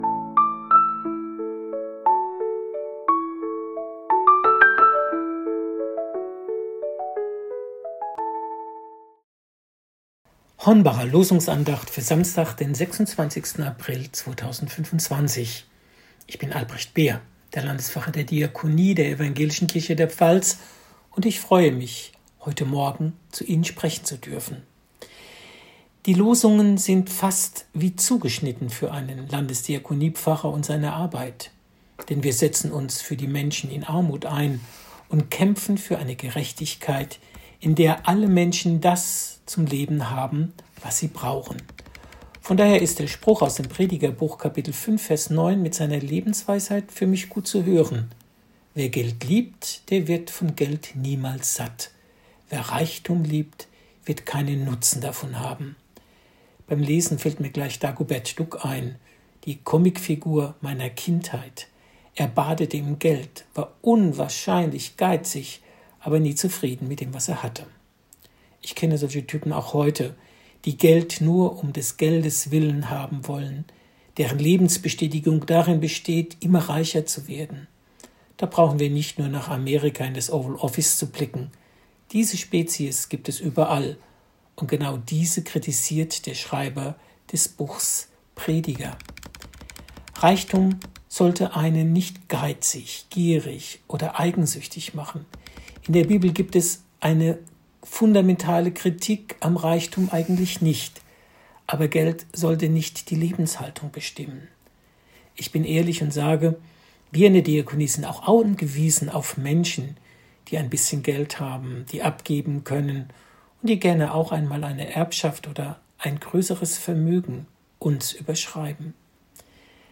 Losungsandacht für Samstag, 26.04.2025